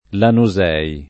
Lanusei [ lanu @$ i ]